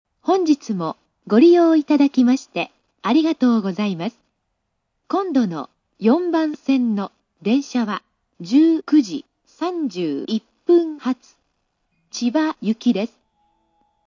スピーカー：National
音質：A
予告放送　(69KB/14秒)